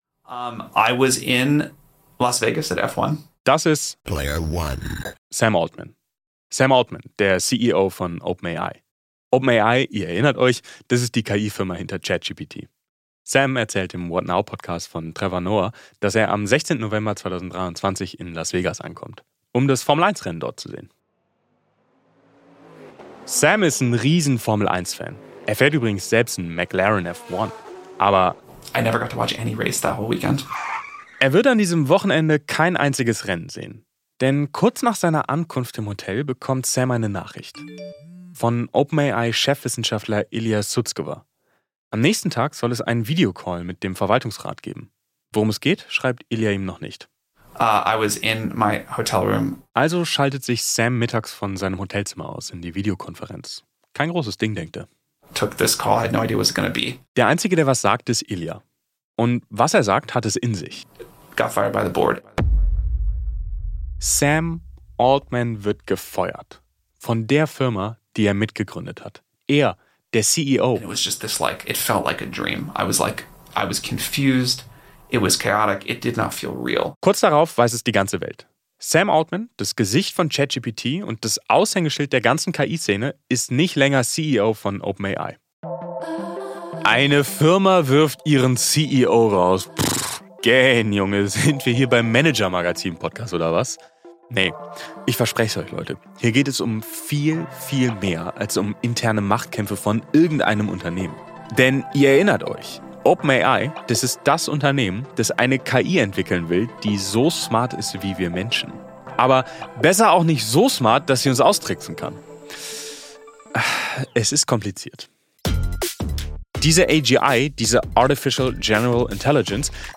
Für diese Episode wurde KI verwendet: ChatGPT für Gespräche und Übersetzungen.